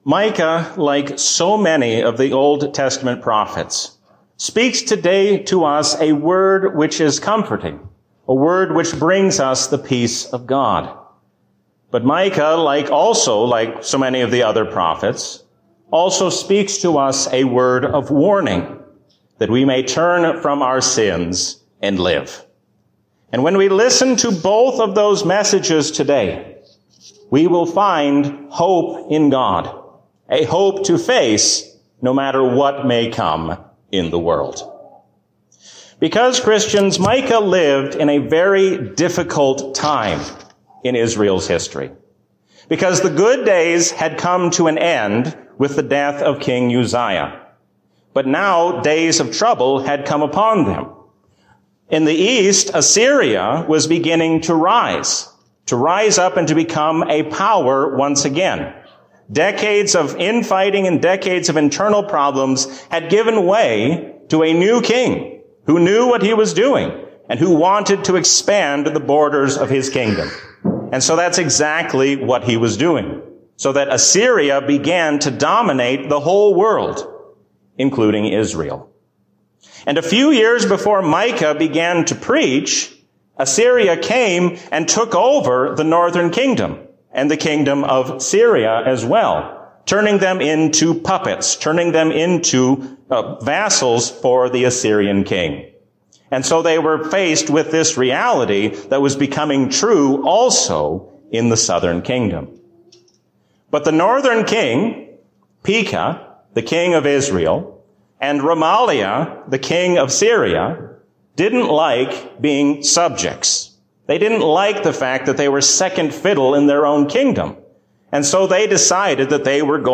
A sermon from the season "Trinity 2021." Stand firm against worldly powers, because Jesus reigns as King forever.